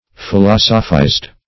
(f[i^]*l[o^]s"[-o]*f[imac]zd); p. pr. & vb. n.